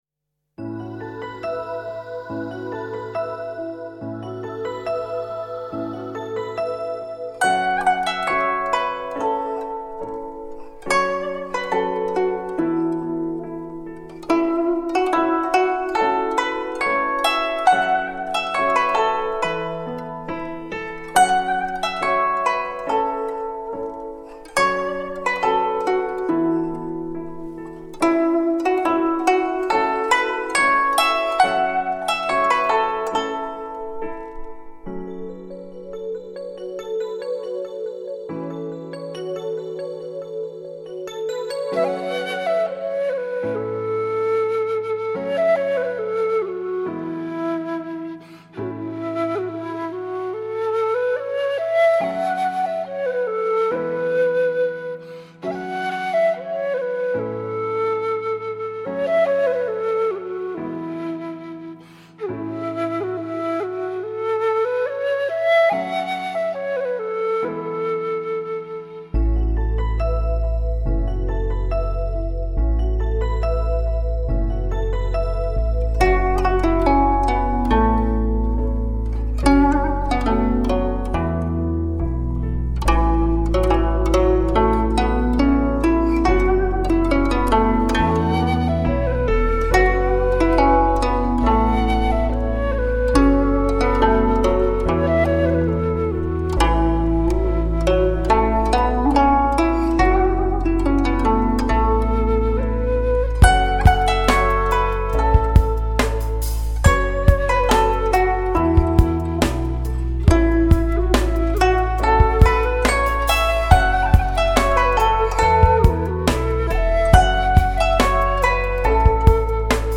古筝
笛子
◆洞穿时空的筝笛合韵
◆HD直刻无损高音质音源技术